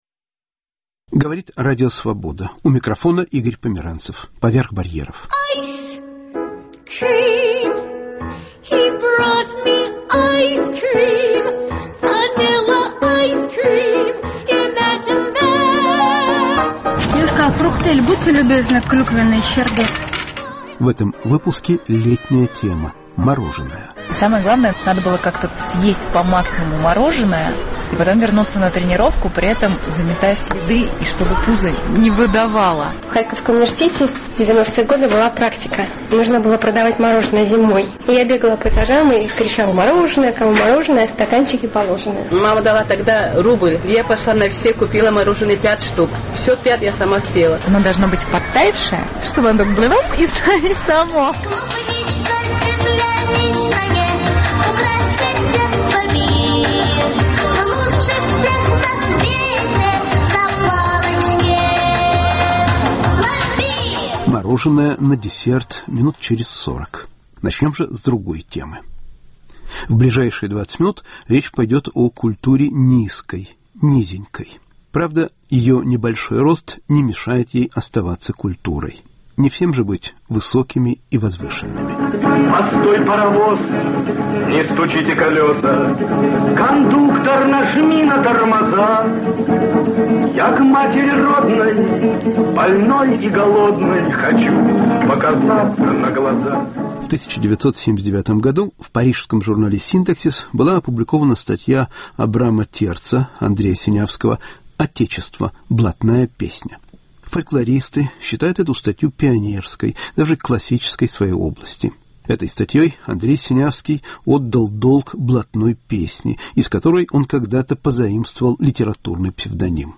"Блатная песня" В передаче звучат голоса филолога и писателя Андрея Синявского (1925-1997), прозаика, автора лагерных песен Юза Алешковского, кинорежиссёра Павла Лунгина и др.